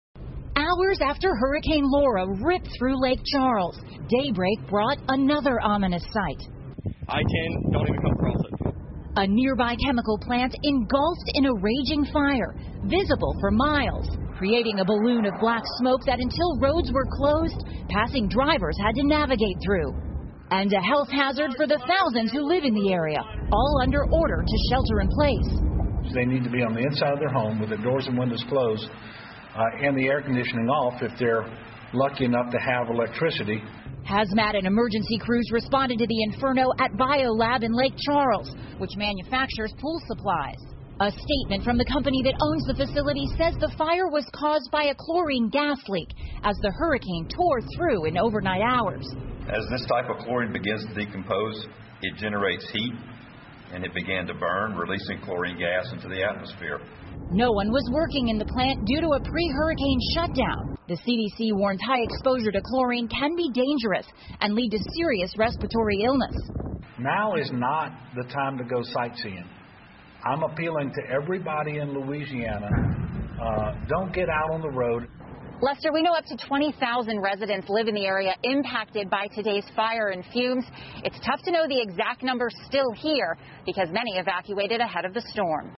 NBC晚间新闻 美国一化工厂严重火灾 听力文件下载—在线英语听力室